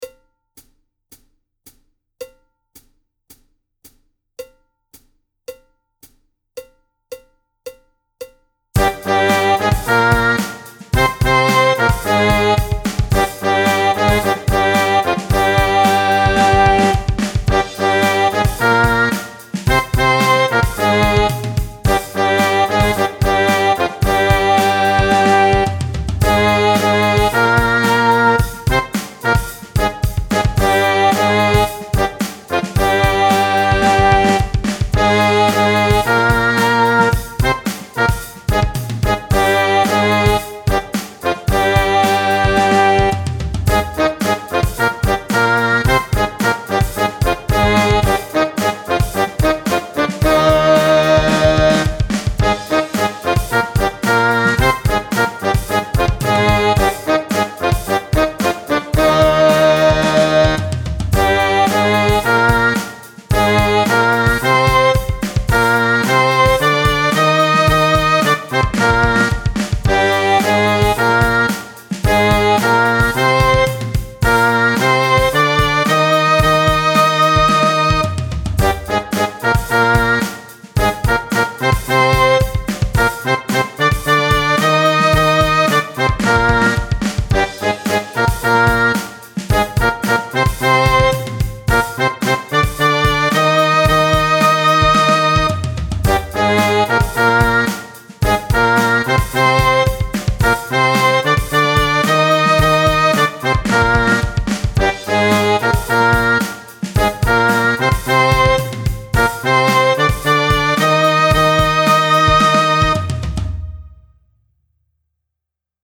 – die Stimmung ist 440Hertz
– der Einzähler des Playbacks beginnt 4 Takte vor dem Song
– das Playback ist aktuell noch eine Midi-Version
Zweistimmige Übung - Tempo 110
Nr. 8 bis 13 | nur 1. Stimme